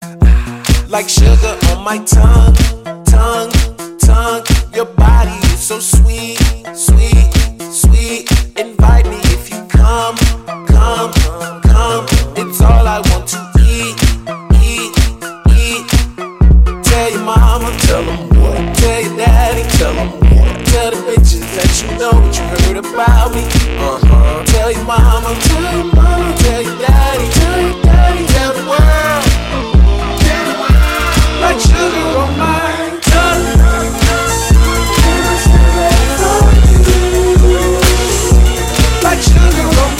Танцевальные рингтоны / Рэп рингтоны
electro-funk hip house
pop rap dance pop